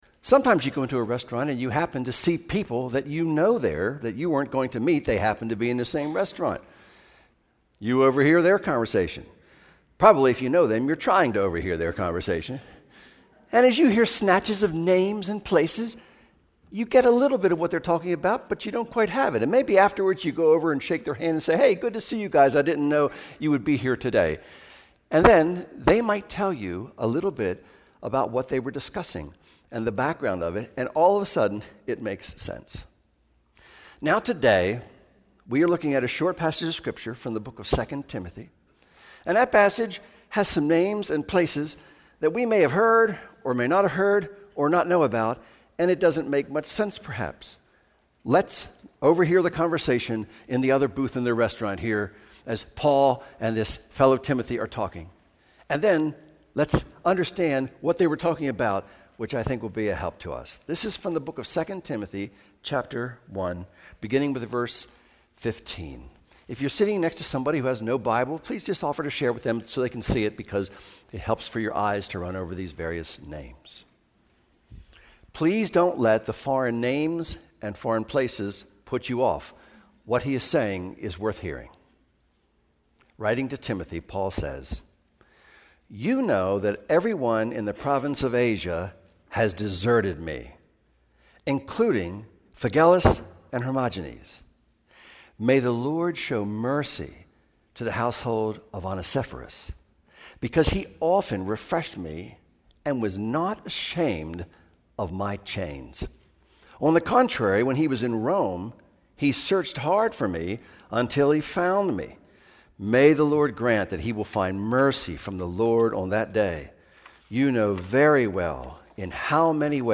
Audio Sermons — Brick Lane Community Church
MP3 audio sermons from Brick Lane Community Church in Elverson, Pennsylvania.